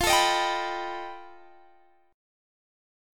Listen to F9b5 strummed